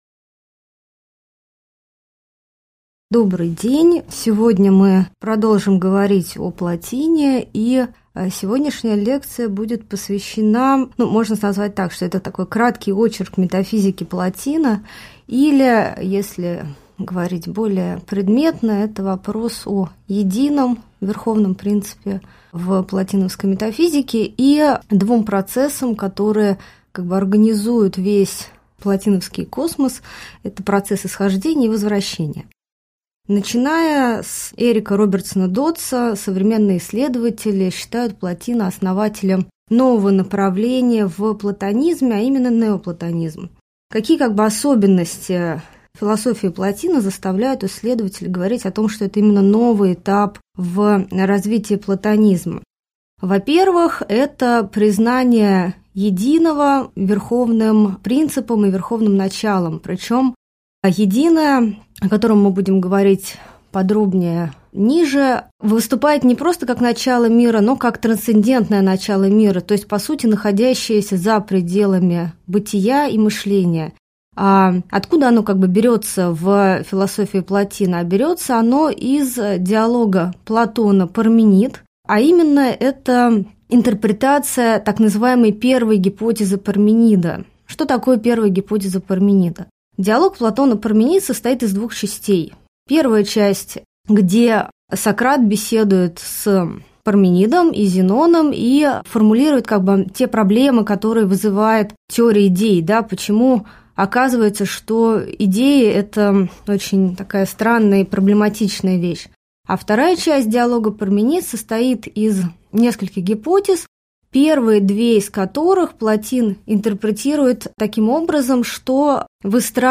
Аудиокнига Лекция «Метафизика первоначала» | Библиотека аудиокниг